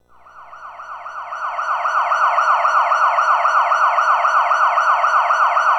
Siren-5s.wav